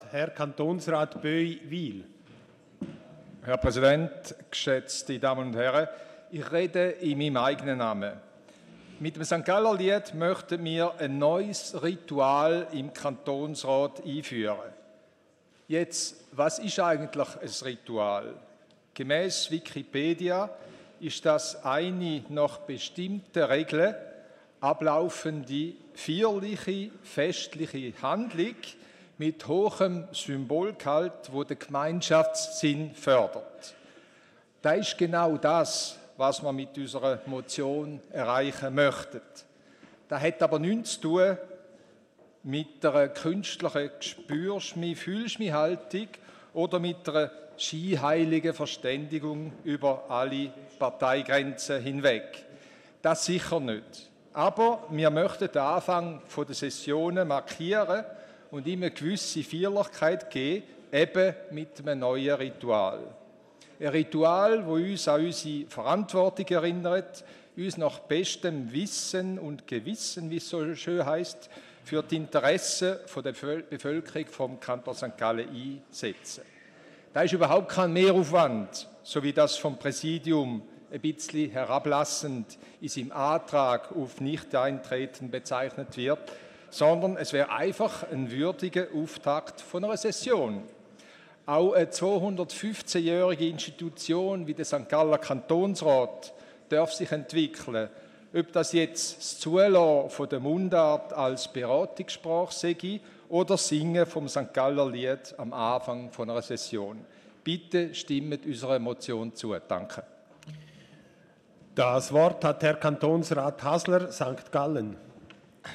Session des Kantonsrates vom 18. und 19. Februar 2019